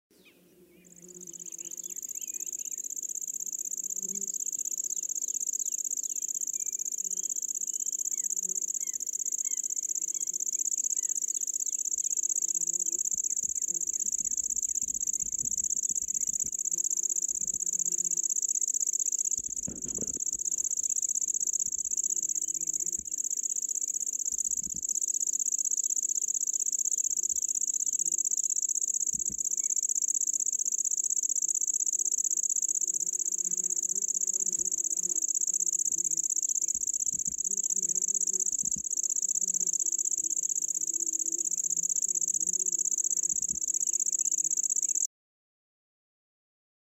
There were no twitchers at all so I managed to get very good
recordings and videos of the bird.